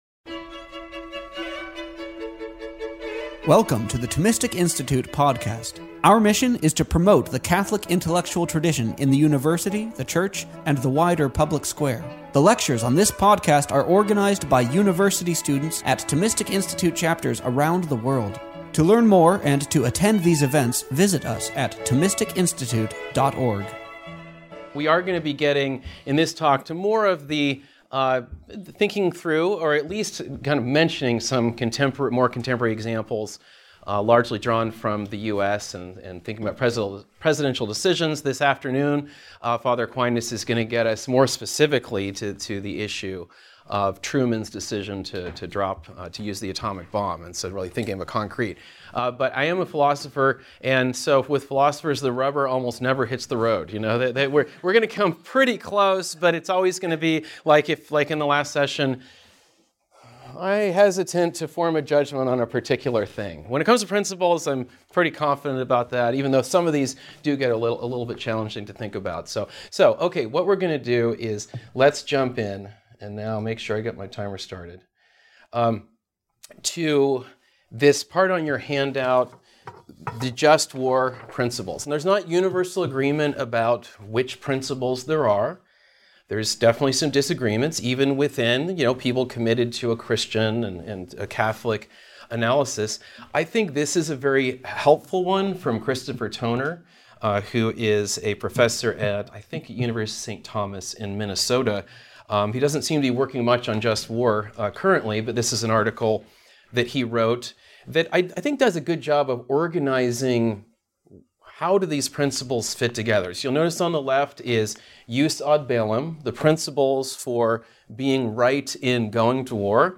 This lecture was given on March 31st, 2023, at a Thomistic Institute Retreat at the Dominican House of Studies.